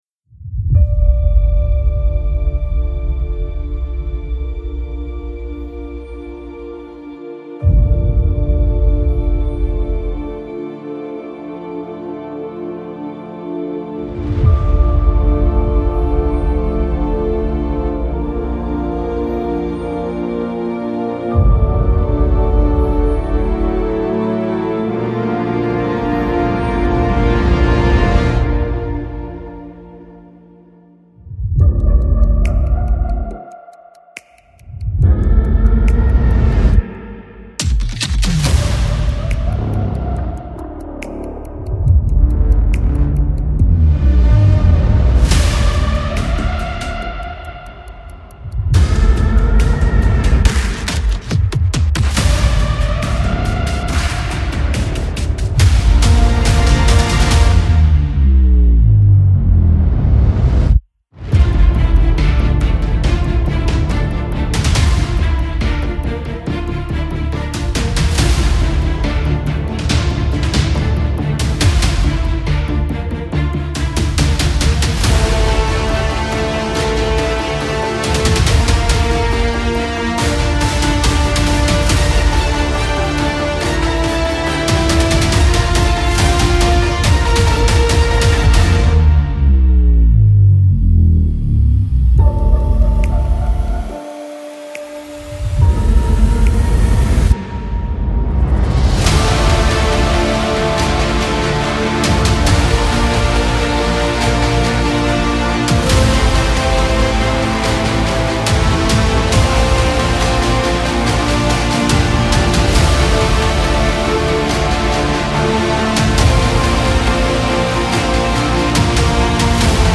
Trailer Music
اپیک , الهام‌بخش , حماسی